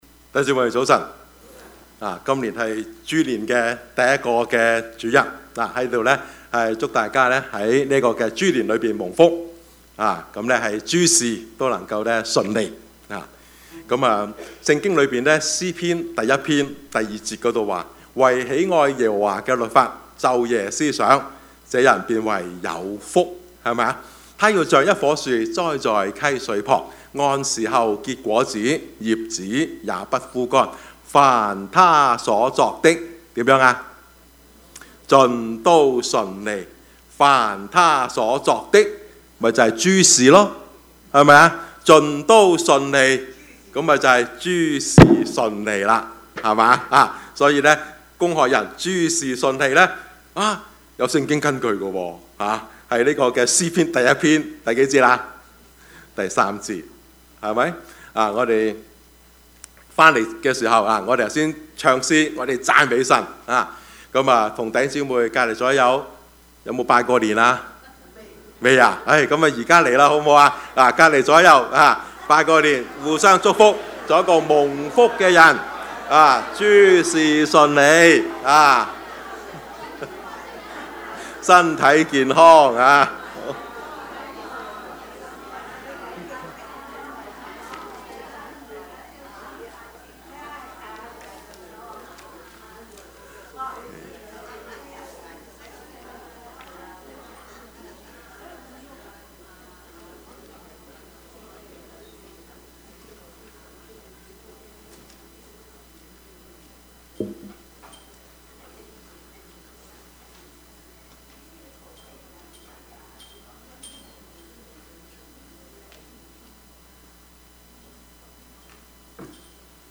Service Type: 主日崇拜
Topics: 主日證道 « 庸人本相 路得–愛的跟隨 »